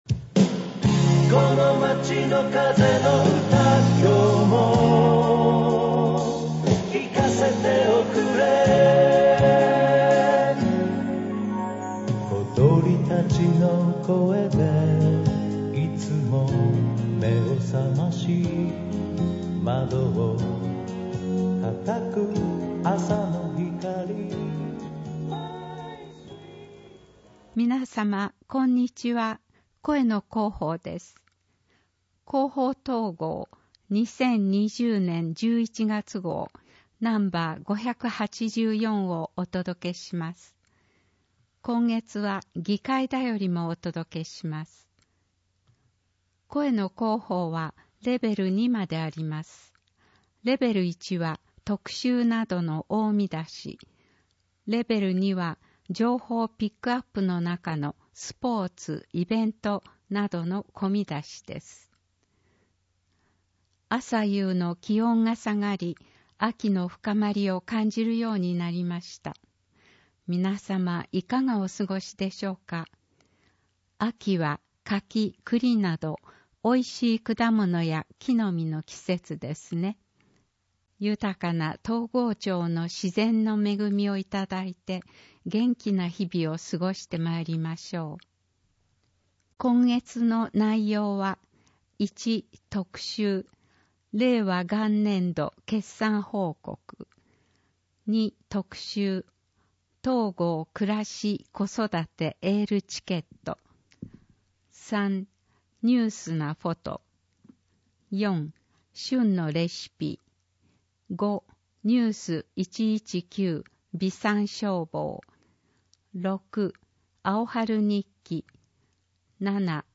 広報とうごう音訳版（2020年11月号）